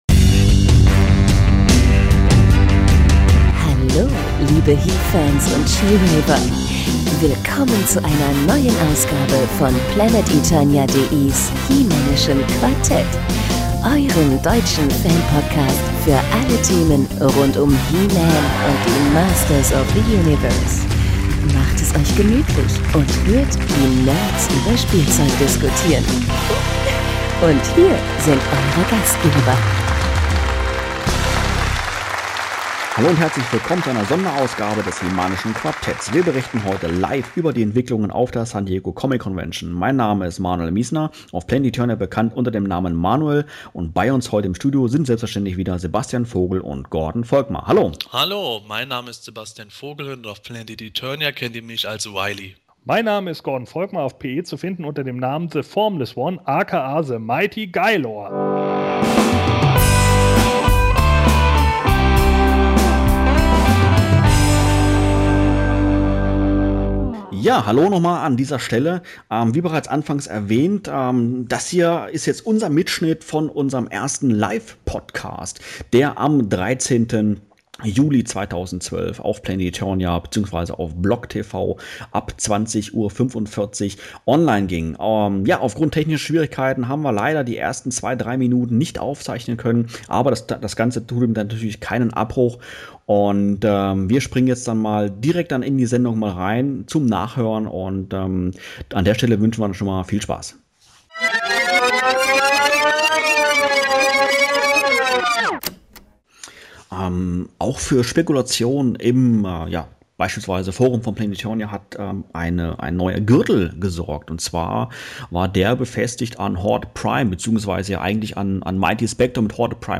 Am 13. Juli 2012 sendete das Quartett zwischen 20:45 Uhr 22:15 Uhr erstmalig anlässlich der San Diego Comic Convention LIVE und der Mitschnitt davon ist jetzt auch zum wieder anhören in iTunes und YouTube sowie als downloadbare MP3-Datei verfügbar.